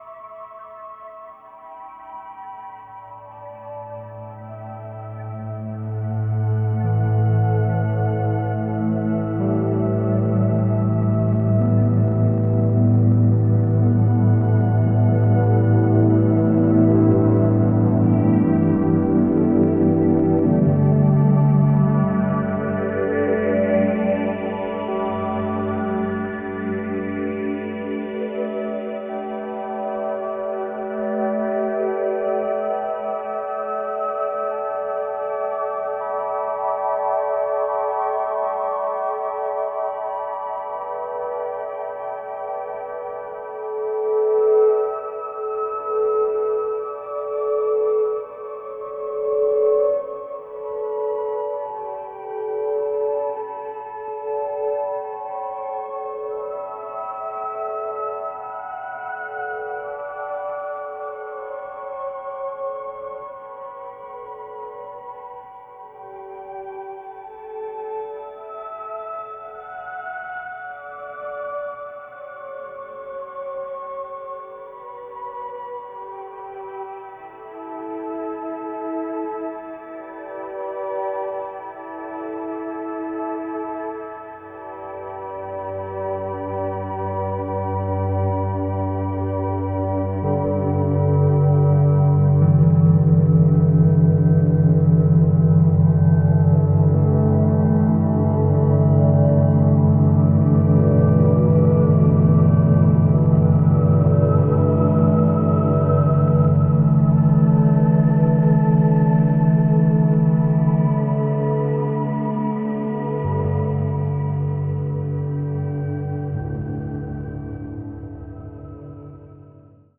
Ambient Rock Folk